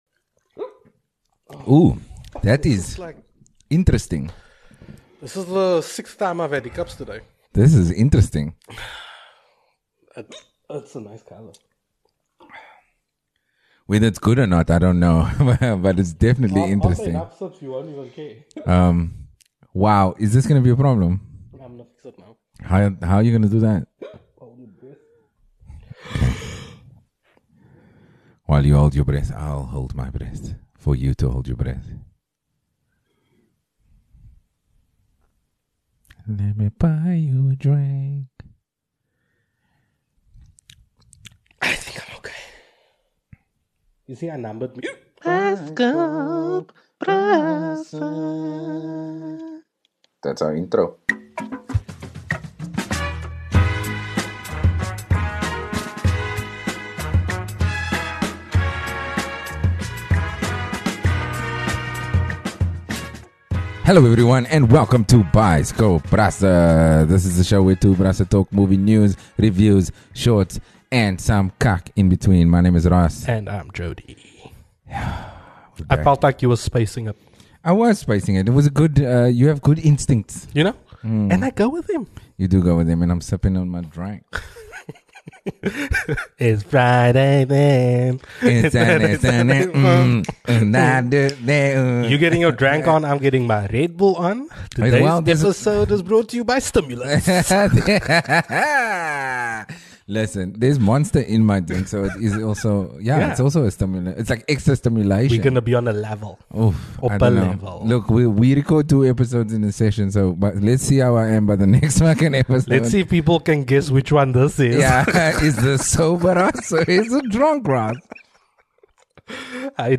Wow, can you now start an episode with hiccups?
Does the world need Boondock Saints 3?, the Brasse and their Irish accents try to figure that out.